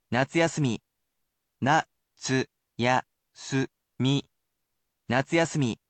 On top of this, I will personally read the new word for you!